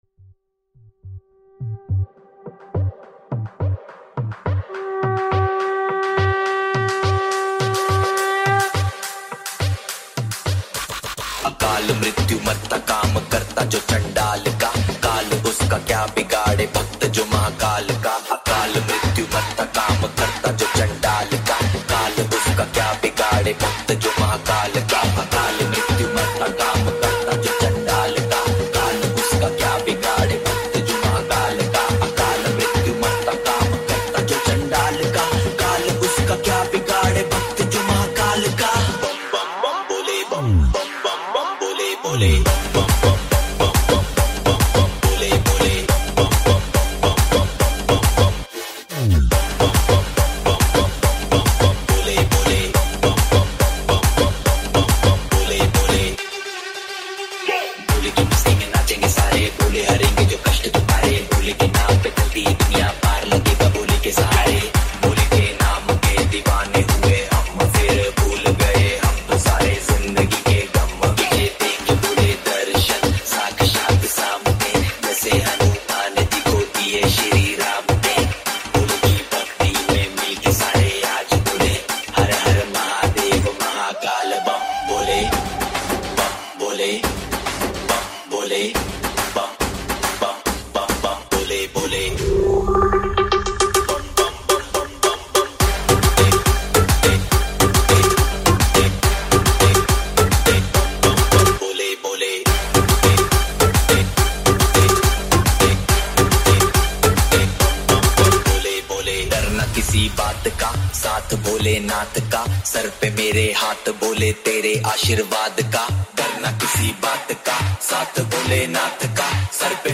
High quality Sri Lankan remix MP3 (12.2).
mixtape